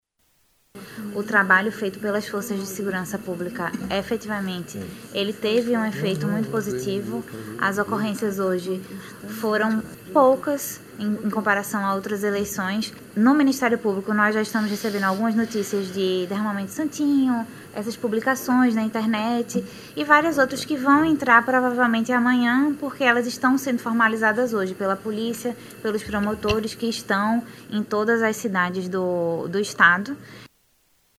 Durante coletiva de imprensa, órgãos envolvidos nas Eleições 2022 divulgaram dados.
Sonora-Catarina-Sales-procuradora-regional-eleitoral.mp3